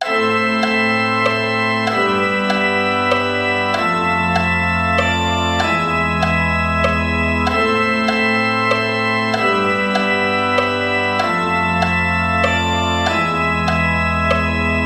De begeleidingen beginnen automatisch weer opnieuw.